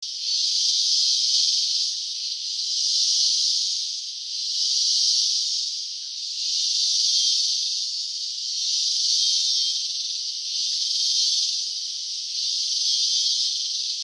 Costa Rica Chrickets
Unnmistakable Jungle Chrickets
Intense sound of Tico crickets around Rio Celeste near Volcan Teneorio in Costa Rica.
Recodered in April 2013 with a regular iPhone 5.
Tenorio Volcano National Park - Rio Celeste, Costa Rica
Tschungel_vulkan_grillen_01.mp3